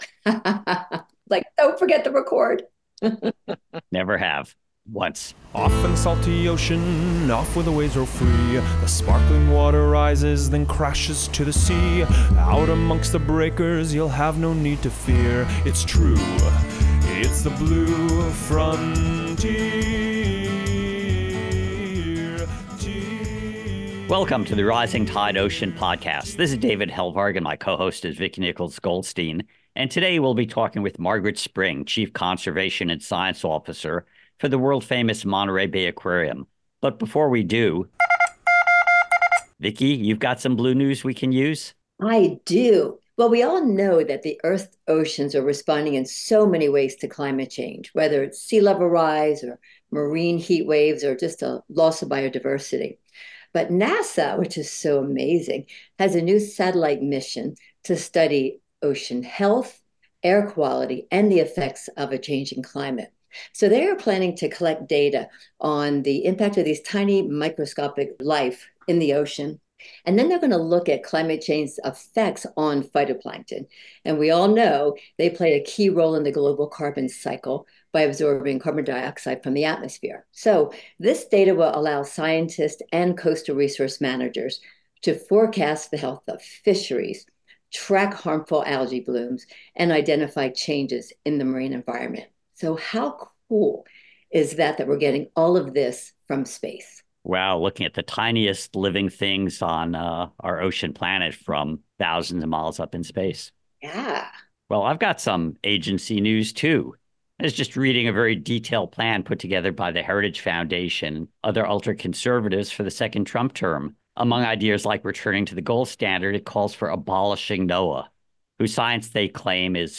Informative, enlightening, and often humorous, it is an invaluable resource for anyone passionate about understanding, enjoying, and protecting our salty blue world.